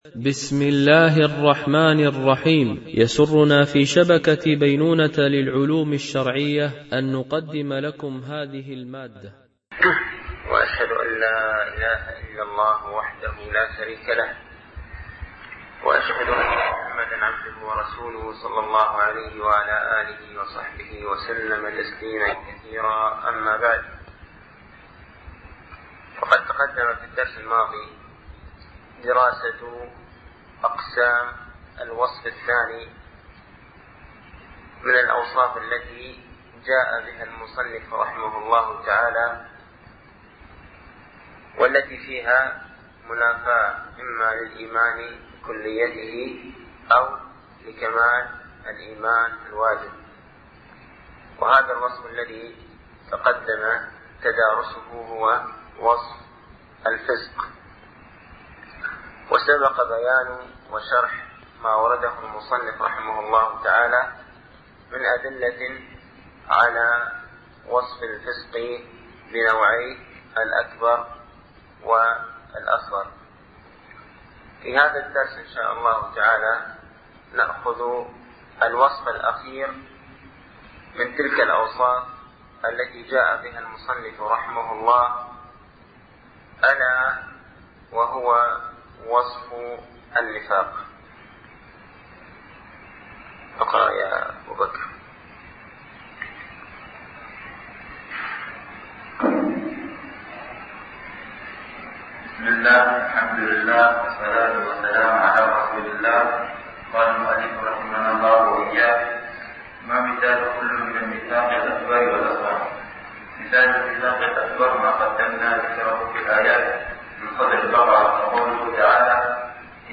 ) الألبوم: شبكة بينونة للعلوم الشرعية التتبع: 93 المدة: 64:24 دقائق (14.78 م.بايت) التنسيق: MP3 Mono 22kHz 32Kbps (CBR)